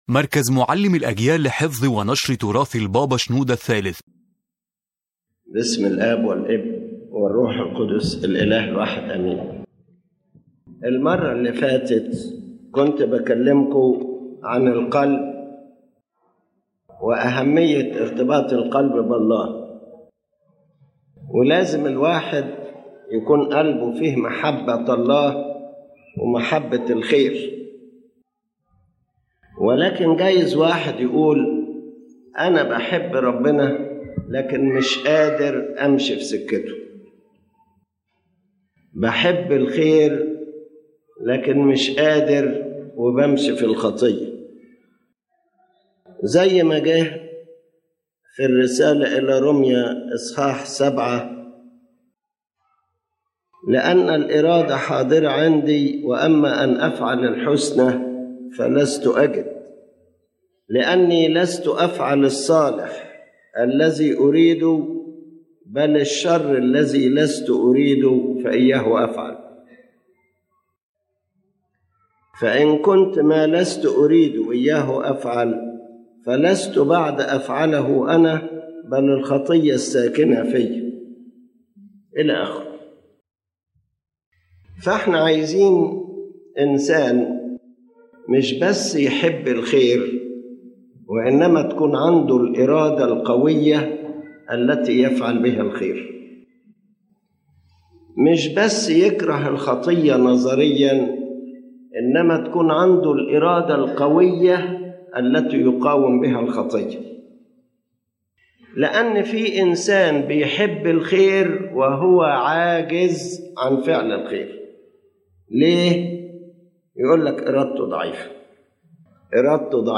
This lecture focuses on the strength of the will in spiritual life, explaining that loving goodness alone is not enough unless it is accompanied by a strong will capable of action and resistance. His Holiness Pope Shenouda III explains that weakness of will is the main reason a person falls into sin despite knowing and desiring what is good.